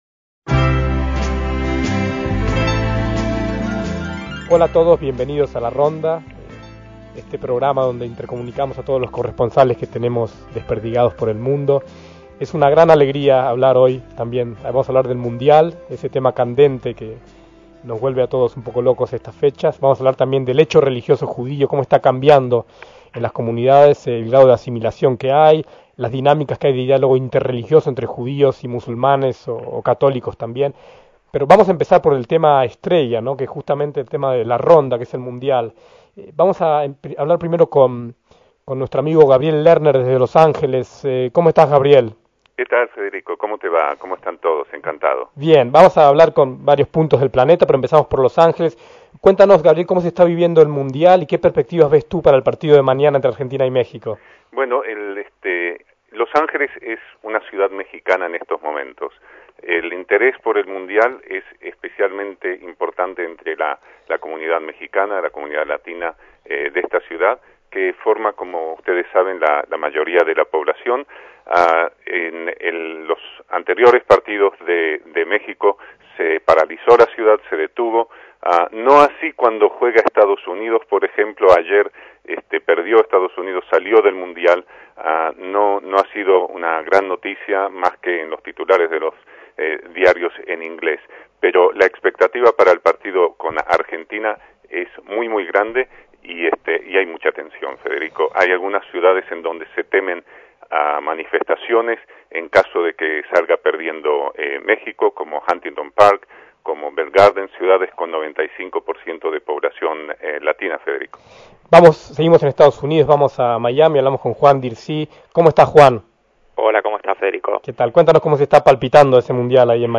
A veces, el deporte acapara casi toda la actualidad, como en esta tertulia de corresponsales de 2006